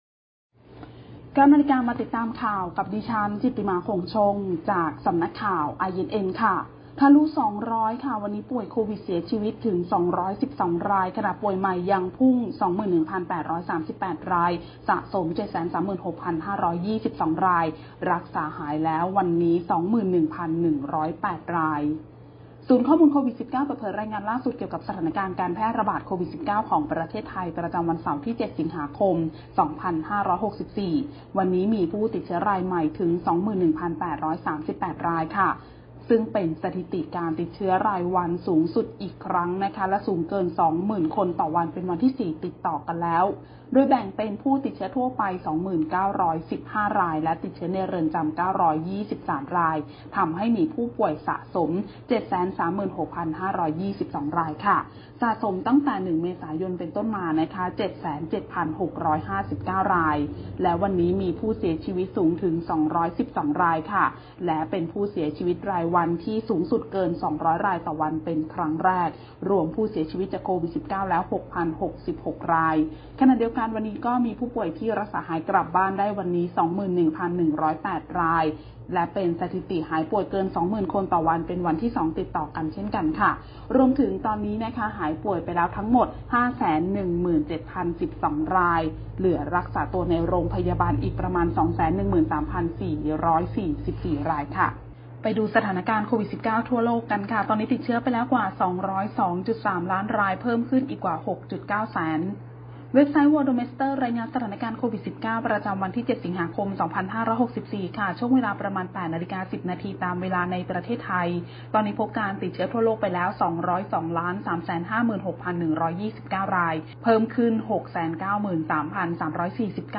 คลิปข่าวต้นชั่วโมง
ข่าวต้นชั่วโมง 09.00 น.